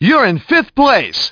Speech
1 channel